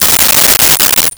Coins Thrown 01
Coins Thrown 01.wav